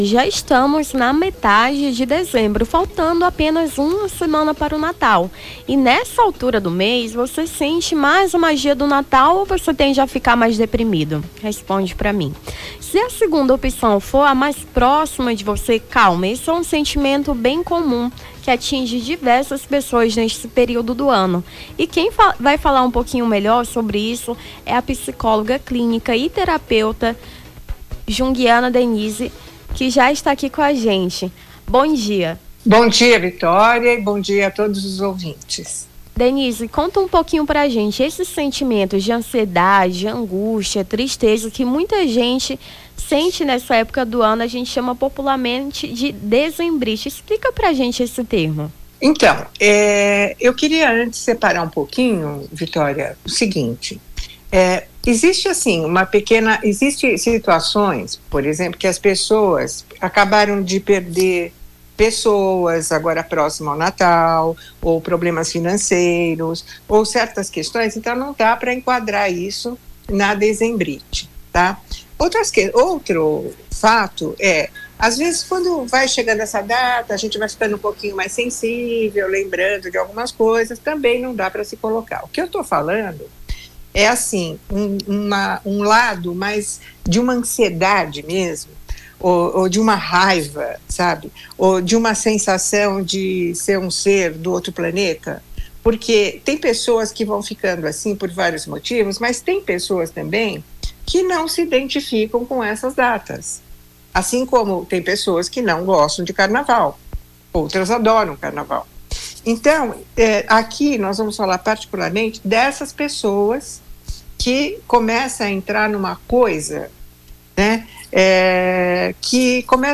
Nome do Artista - CENSURA - ENTREVISTA DEZEMBRITE (18-12-24).mp3